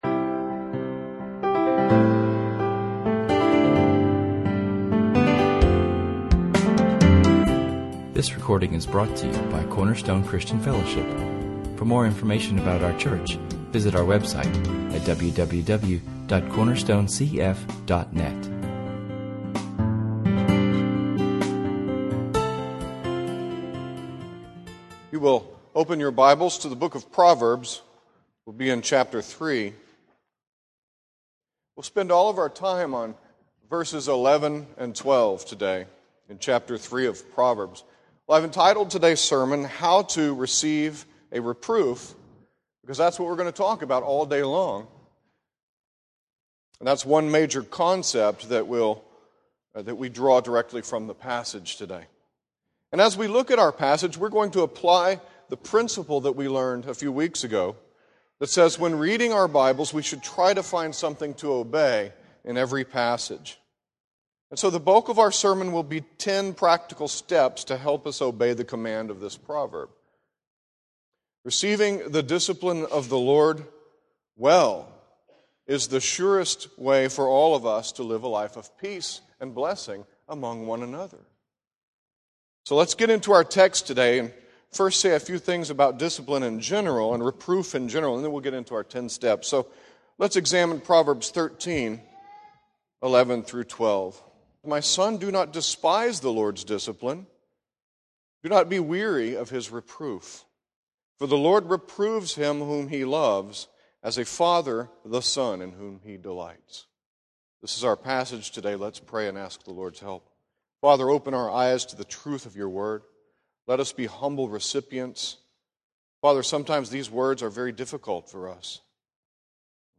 In this sermon, we examine the idea that a reproof if God’s good medicine for us, and most likely, this medicine is delivered by a friend who is risking a great deal to bring a reproof to you. We learn that discipline must be viewed Biblically as grace, rather than worldy as conflict.